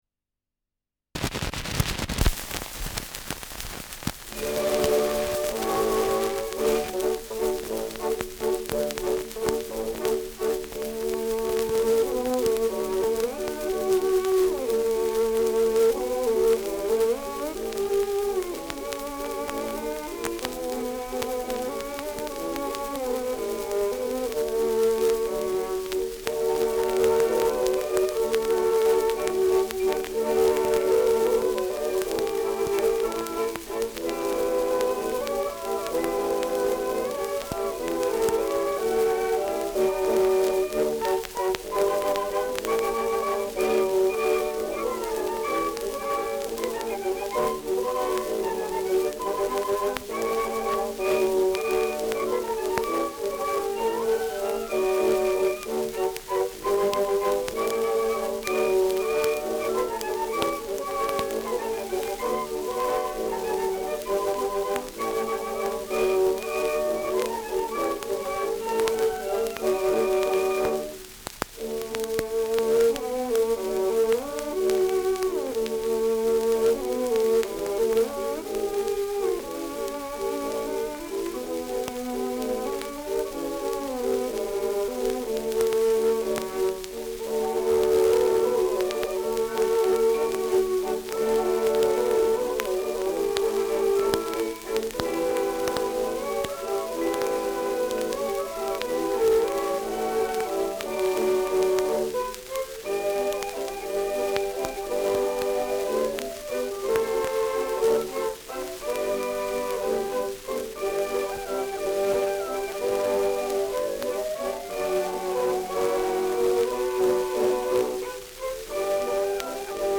Schellackplatte